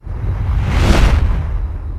咻的一下飞过的影视音效免费下载
SFX音效